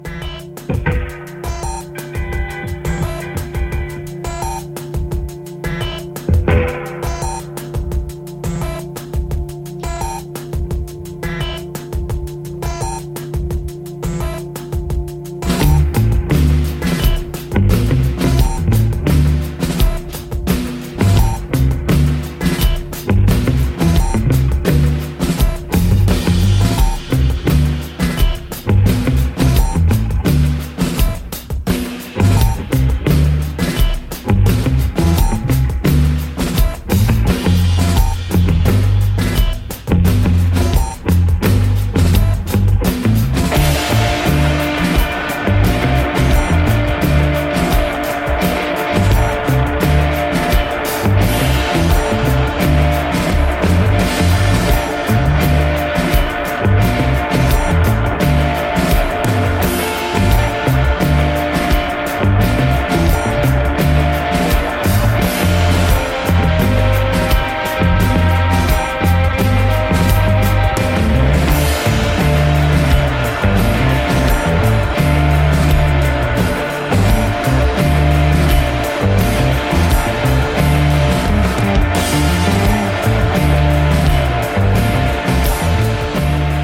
Electro Punk, Dark Wave